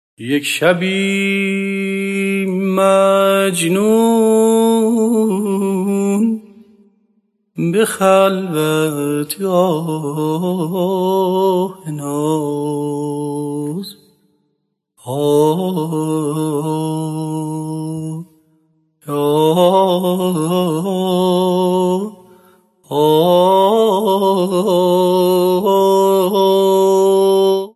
À cette intervalle structurel succède une phrase contigüe et descendante jusqu'à la note de repos (Ist) (voir la figure ci-dessous).
De façon générale, l'une des caractéristiques mélodiques de Masnavi est l'usage poussé d'ornements et de Tahrirs. (Ci-dessous le premier hémistiche du Masnavi interprété par Ostâd Karimi).